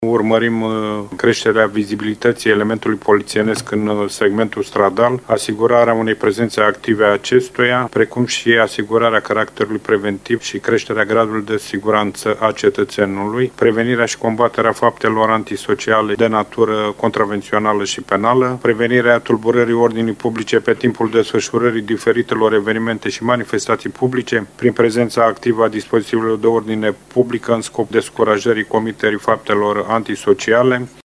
Stadiul pregătirilor a fost prezentat în ședința Colegiului Prefectural pentru luna noiembrie, de reprezentanții inspectoratelor de Poliție și Jandarmi, ISU, Protecția Consumatorului și cei de la control sanitar veterinar.